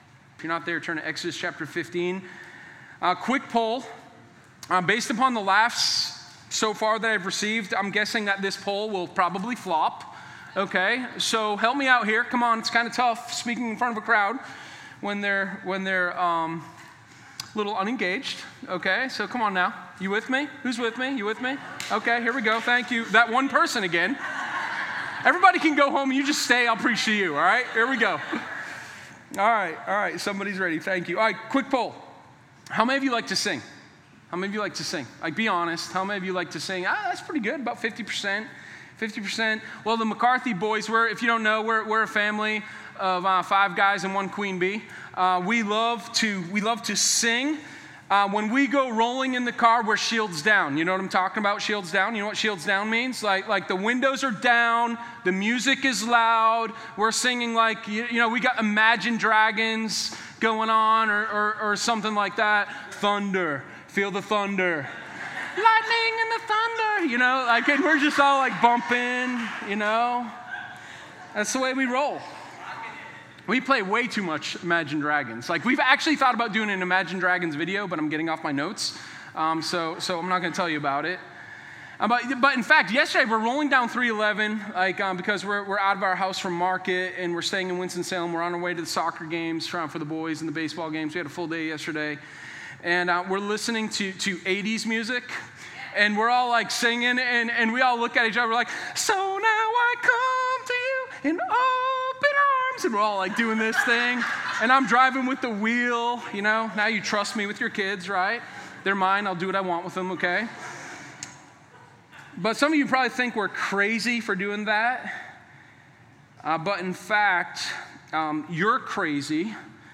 Sermon0415_Song-at-the-Sea.mp3